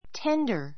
tender téndə r テ ンダ 形容詞 ❶ （肉などが） やわらかい; か弱い, デリケートな This steak is very tender.